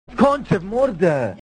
Worms speechbanks